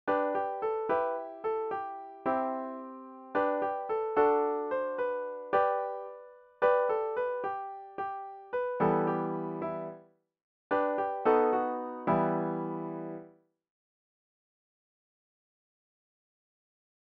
Nahrávky živých muzikantů si můžete poslechnout u písniček Vločka a Jinovatka, u ostatních si můžete poslechnout zatím jen melodie generované počítačem 🙂